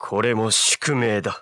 File:Meta Knight voice sample JP.oga
Meta_Knight_voice_sample_JP.oga.mp3